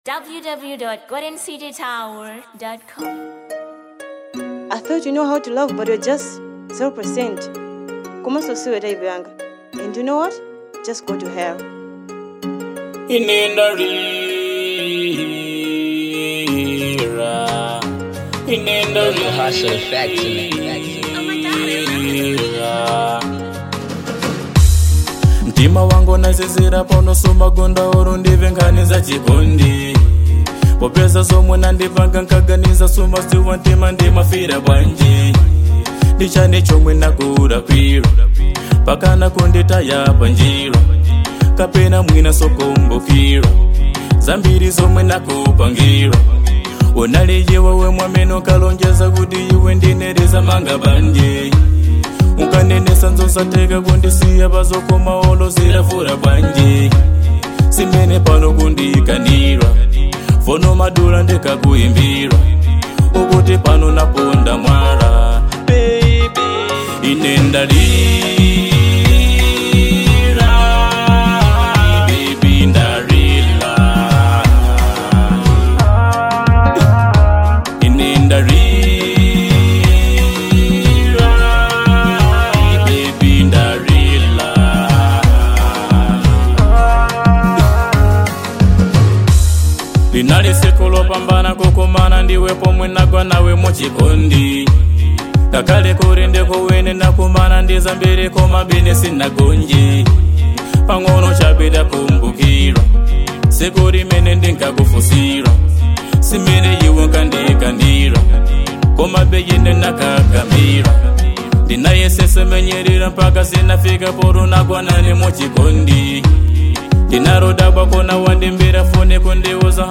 2. Afro Pop